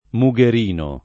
mugherino [ mu g er & no ]